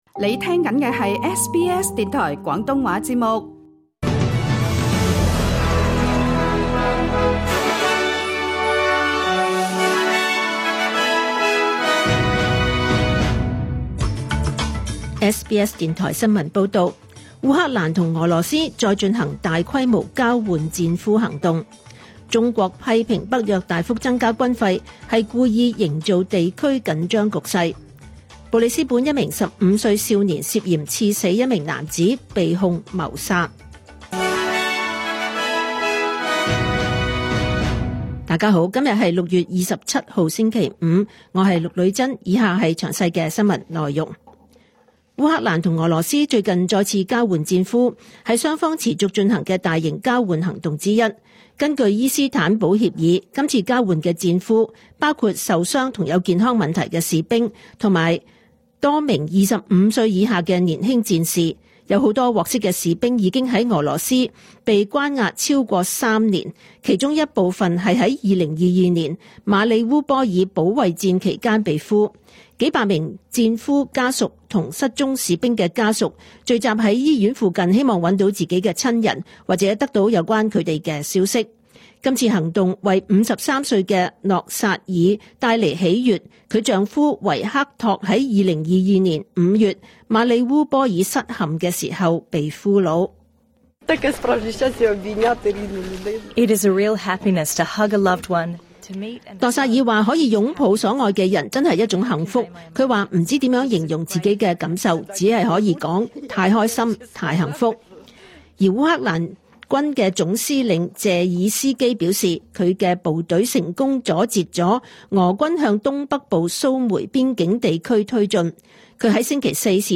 2025 年 6 月 27 日 SBS 廣東話節目詳盡早晨新聞報道。